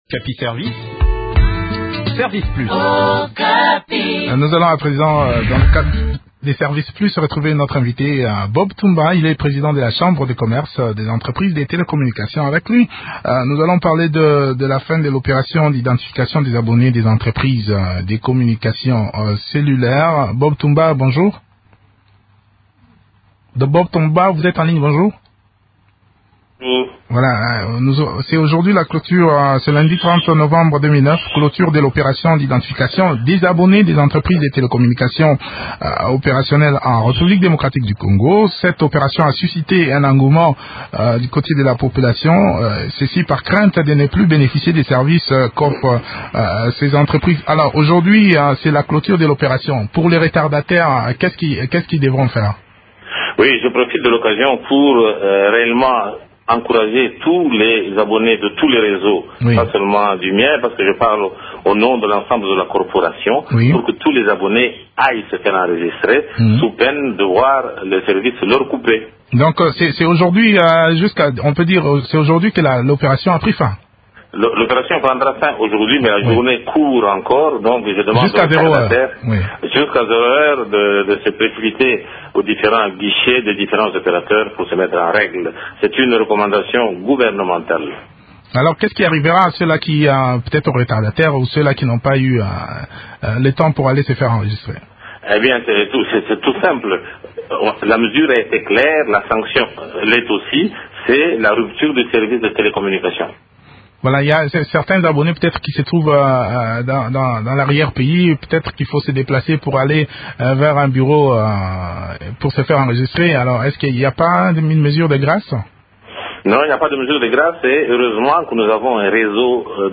s’entretient sur le sujet